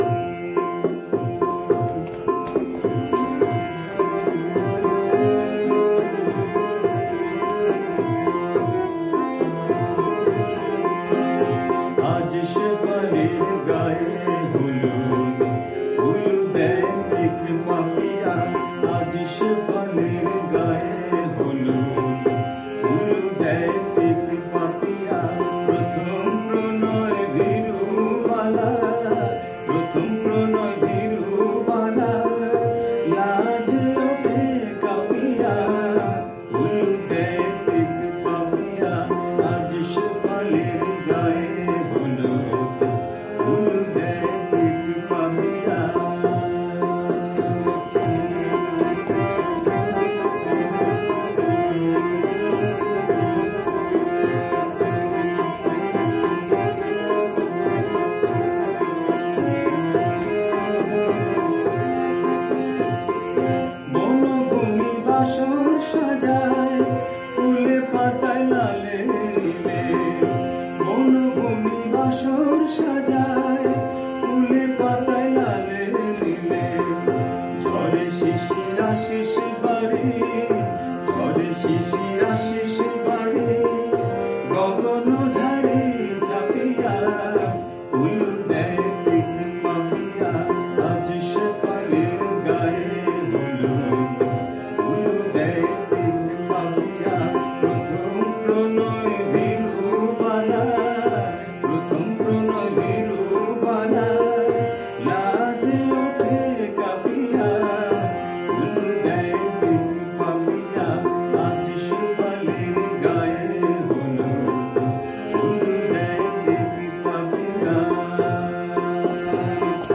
পিলু-কার্ফা।